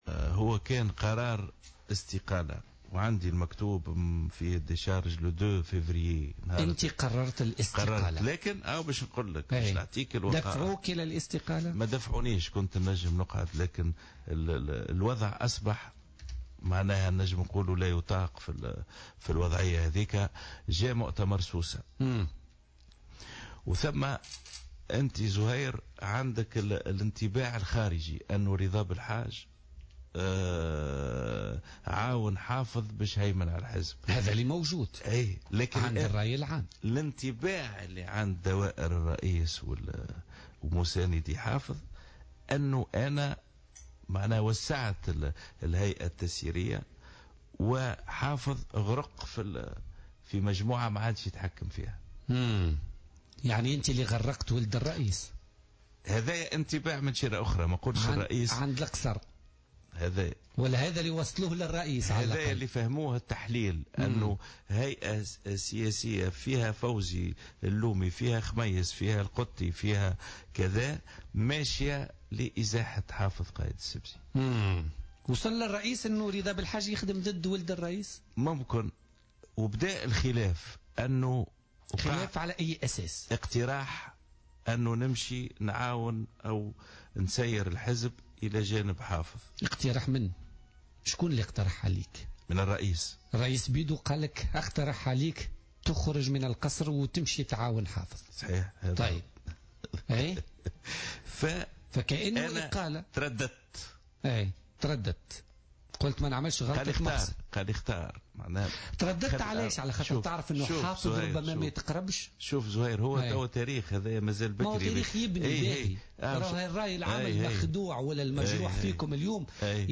وأضاف بلحاج، ضيف برنامج "بوليتيكا" اليوم أنه قرّر الانسحاب من القصر الرئاسي بعد توتّر الوضع داخل الحزب في تلك الفترة، خاصة بعد اتهامه بتوسيع الهيئة السياسية لإزاحة حافظ قائد السبسي، مشيرا إلى أن رئيس الجمهورية آنذاك خيّره بين البقاء في القصر وتسيير الحزب إلى جانب ابنه.